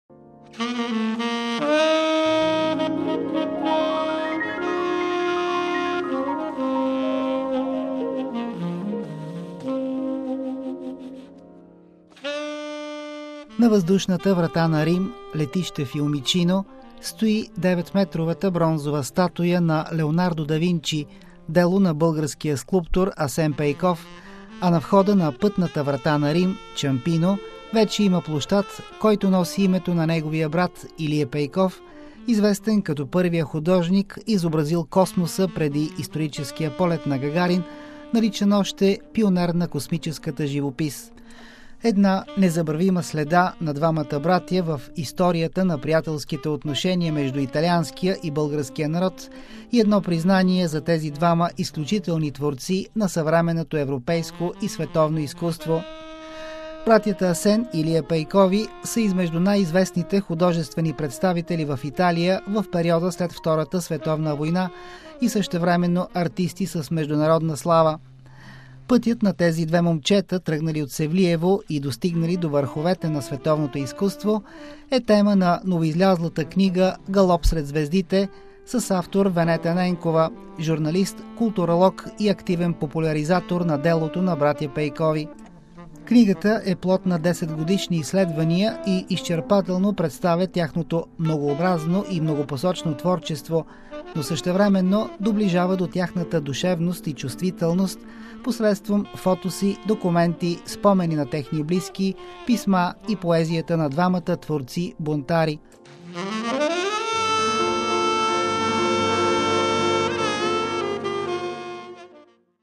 В интервю